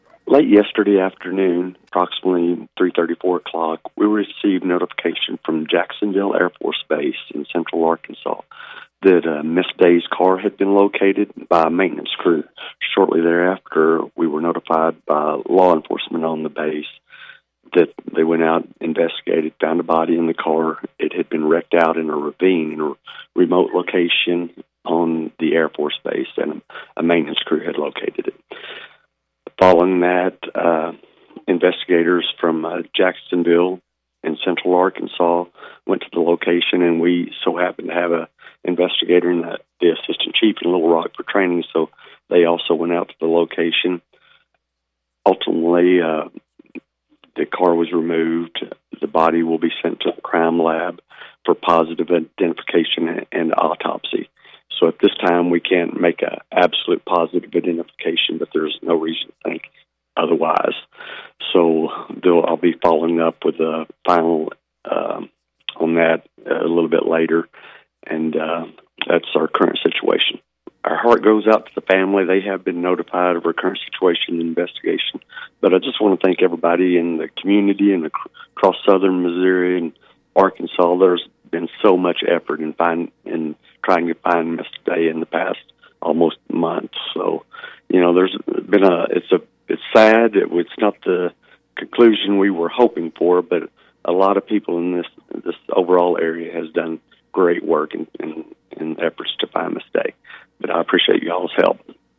Chief Griffin gave the following report on KTLO’s 7 Newscast.